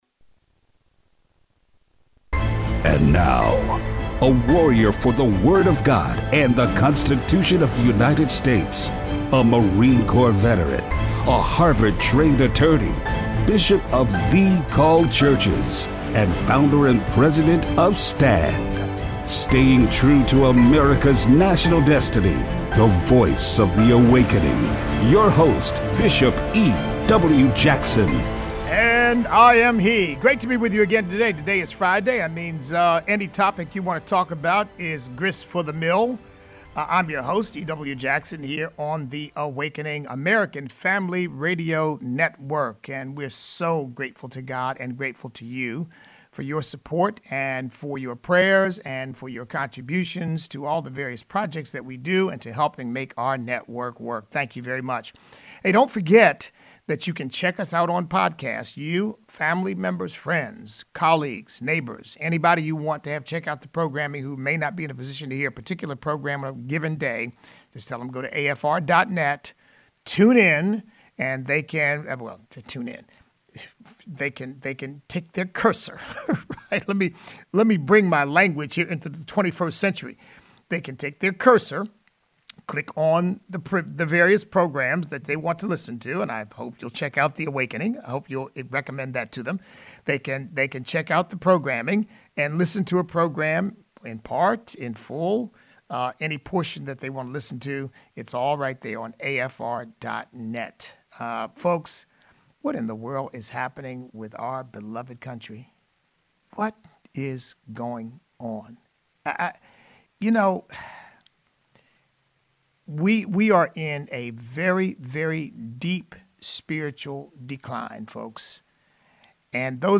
Listener call-in